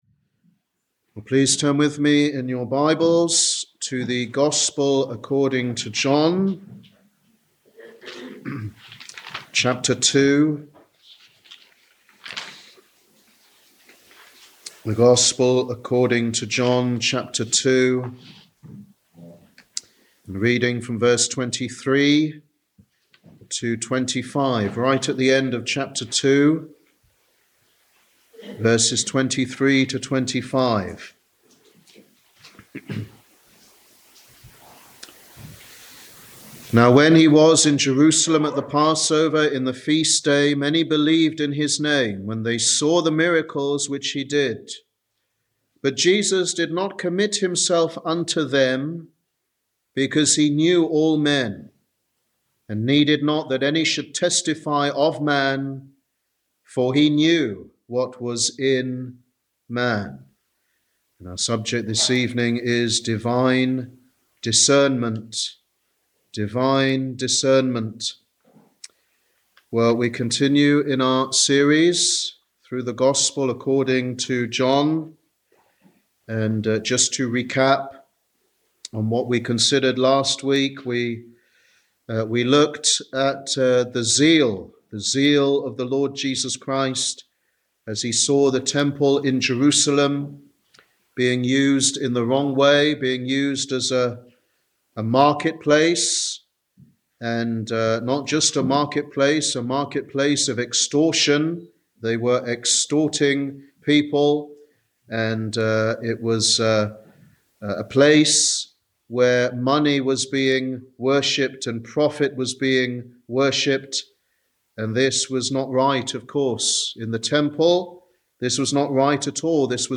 Sunday Teaching Ministry
Sermon